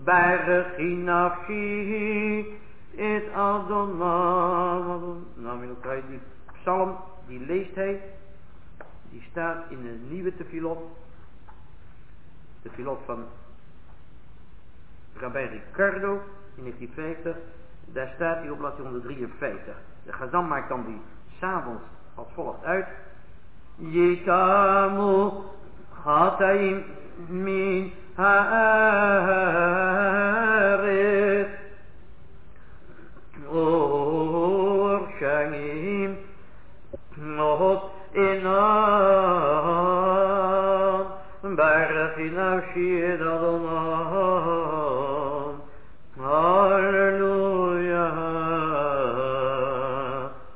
Chazzan without tallit.
All sing  (recording DNN)
Chazzan repeats last line and all continue to sing Lamnatseach
On Rosh Chodesh